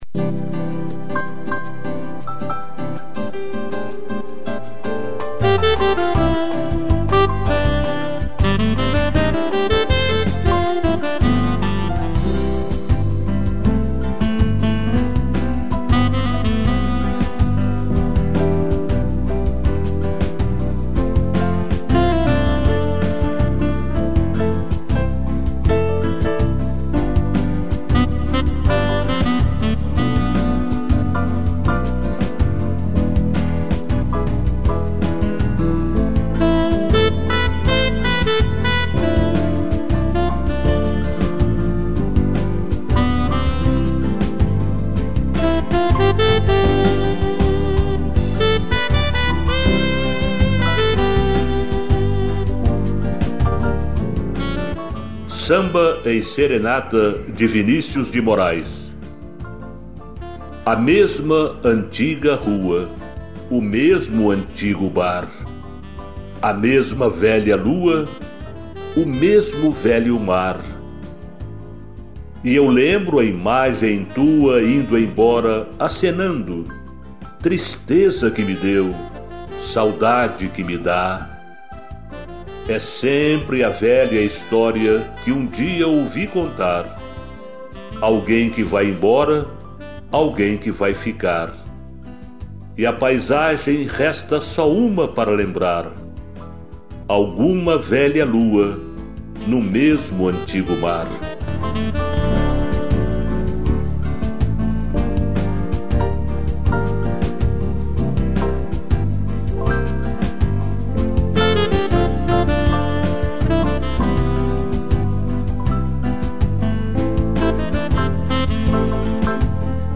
piano e sax